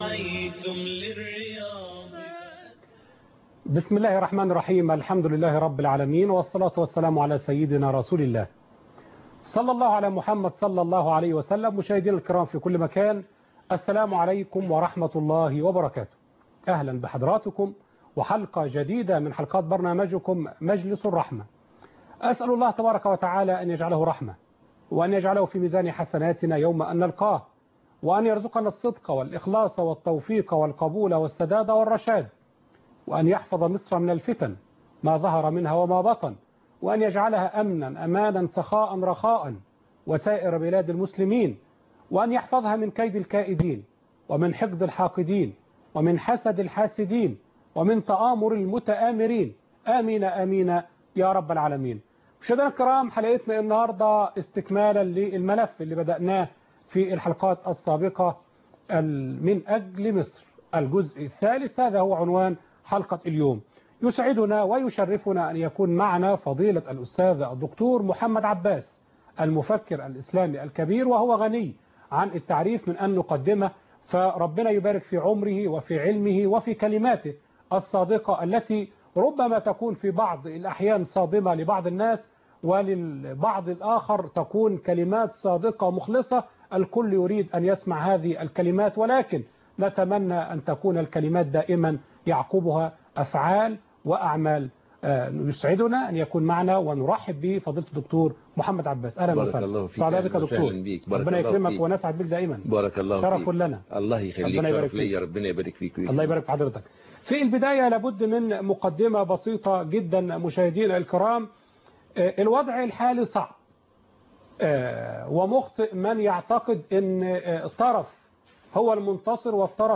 من أجل مصر--لقاء